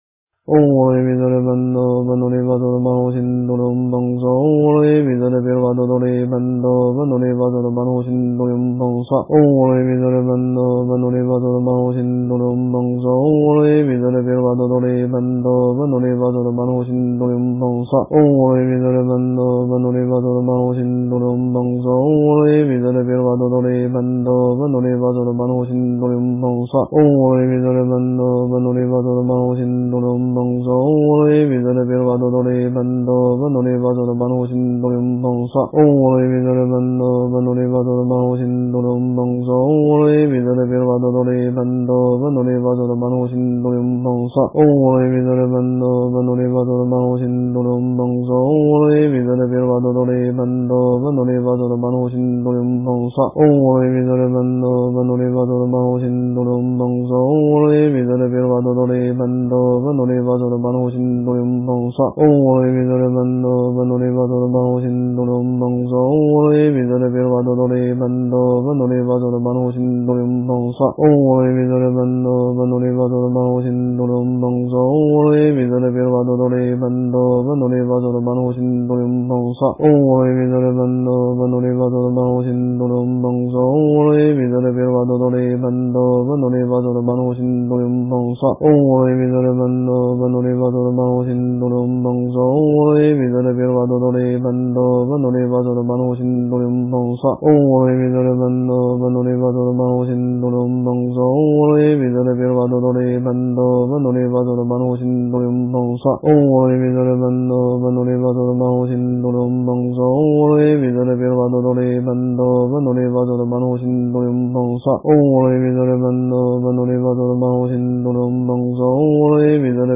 诵经
佛音 诵经 佛教音乐 返回列表 上一篇： 地藏经-如来赞叹品第六 下一篇： 地藏经卷下 相关文章 普贤十大愿--悟因法师 普贤十大愿--悟因法师...